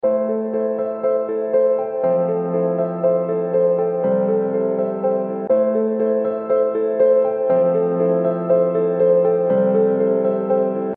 Производим запись, и делаем два трека, сперва идет запись от M50, а после от HD 650.
Во втором треке на 16 дБ.
При поднятии на 16 дБ получаем равную громкость.